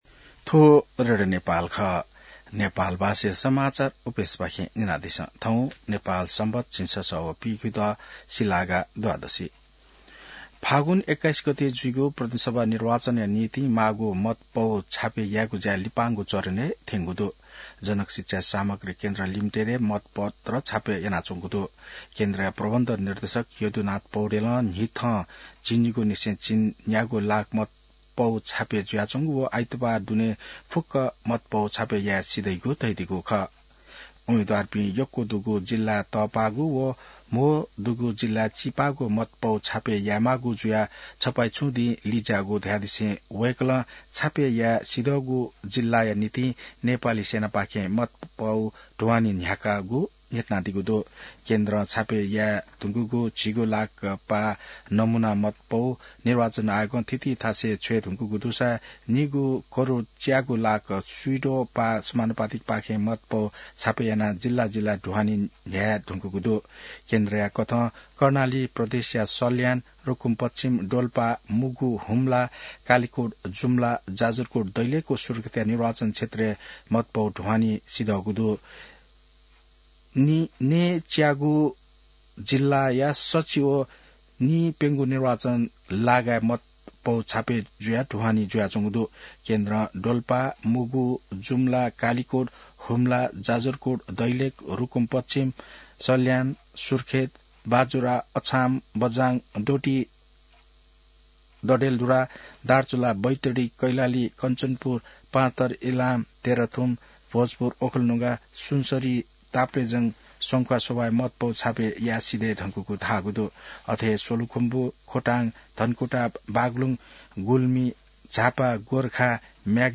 नेपाल भाषामा समाचार : २ फागुन , २०८२